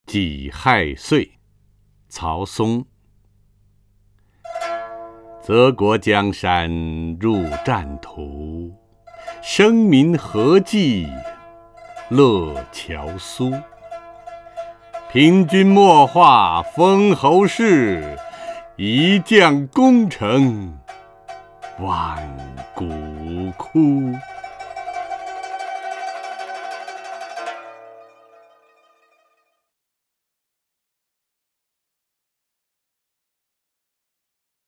陈铎朗诵：《己亥岁·其一》(（唐）曹松) (右击另存下载) 泽国江山入战图， 生民何计乐樵苏。
（唐）曹松 文选 （唐）曹松： 陈铎朗诵：《己亥岁·其一》(（唐）曹松) / 名家朗诵欣赏 陈铎